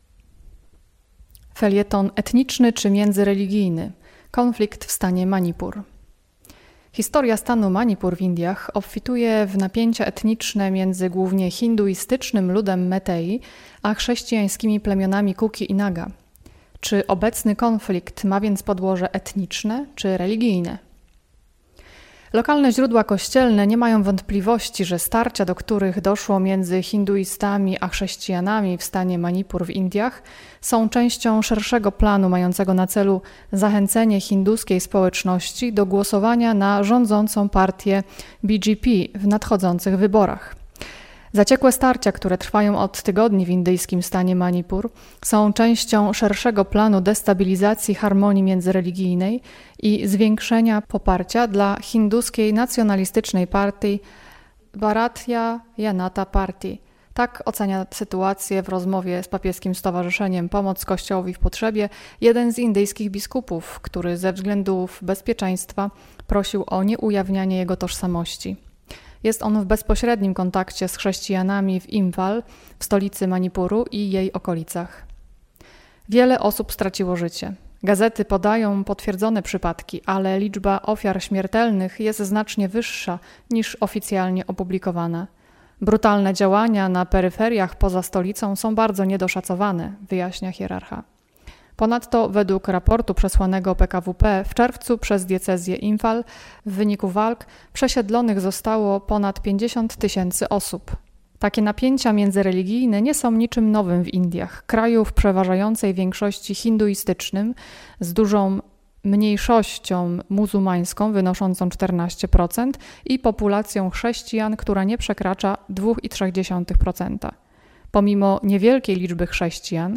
felieton-Indie.-Etniczny-czy-miedzyreligijny-Konflikt-w-stanie-Manipur.mp3